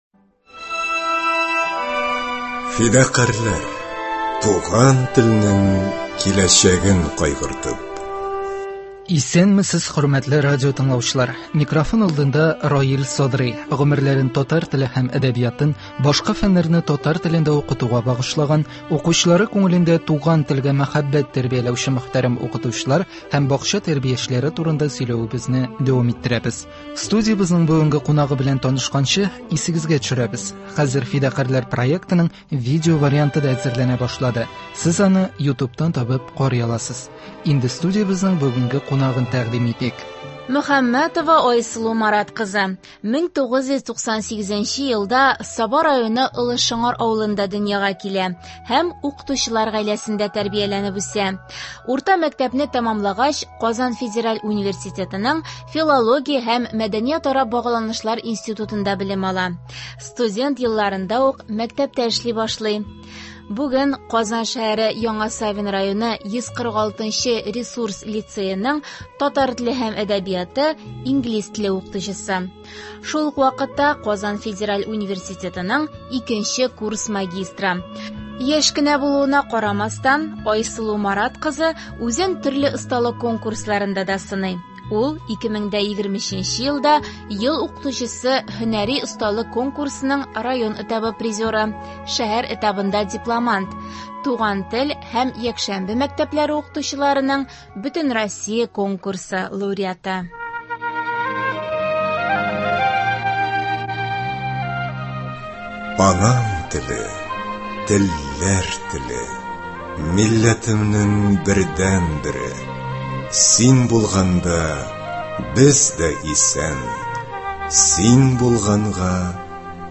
Студиябезнең бүгенге кунагы